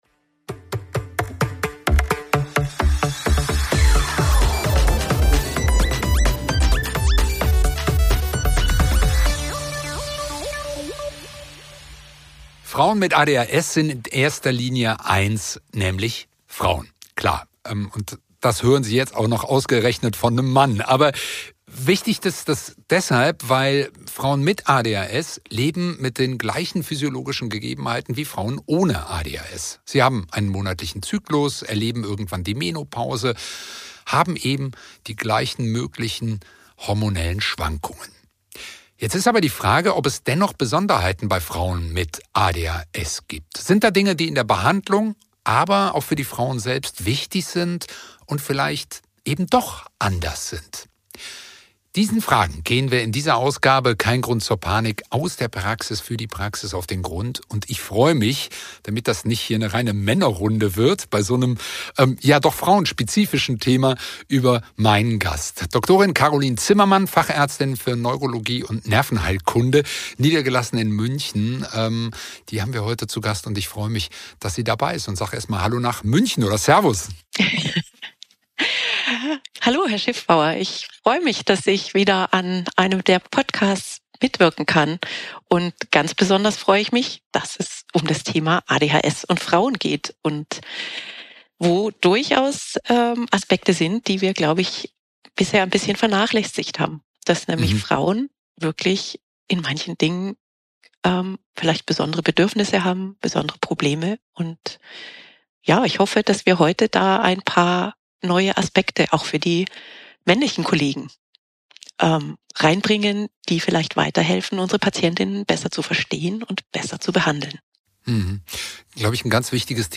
In abwechslungsreichen Podcasts beantworten Experten verschiedener Fachdisziplinen relevante Fragen zum Thema ADHS.